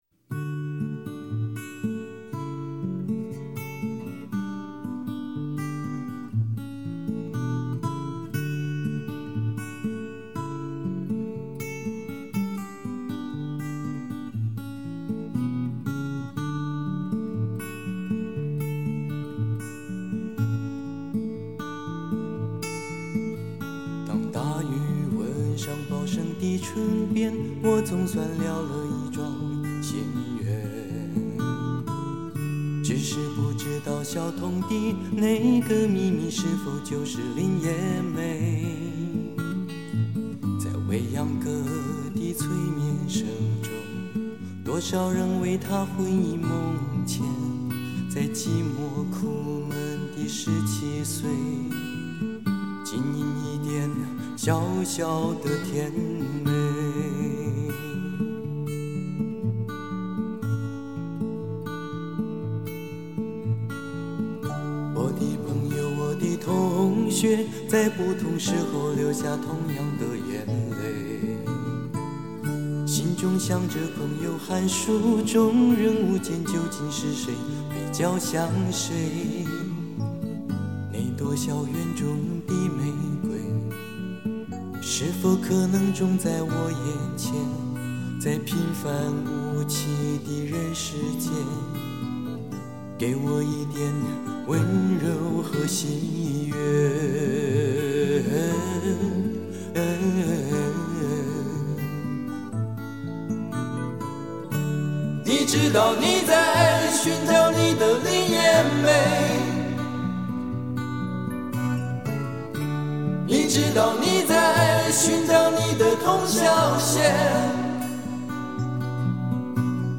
原版原唱